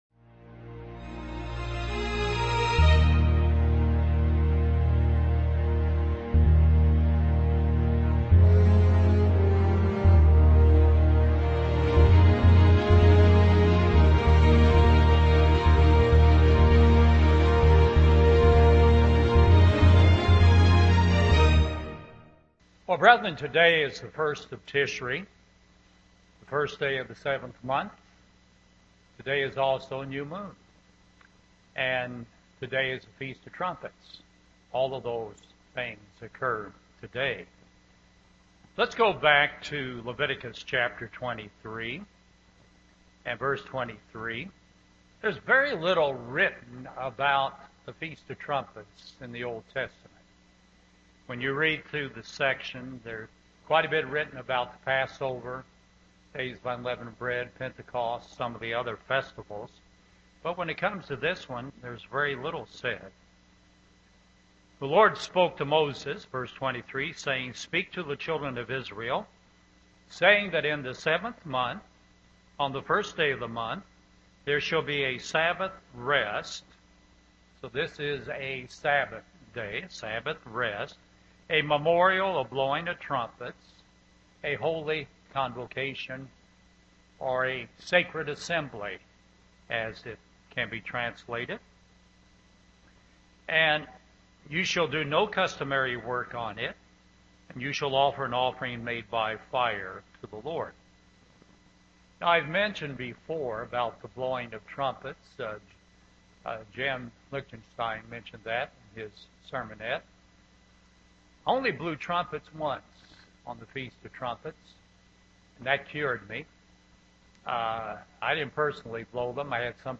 Our faith in God and His promises should motivate us to live as if we believe these things will take place UCG Sermon Transcript This transcript was generated by AI and may contain errors.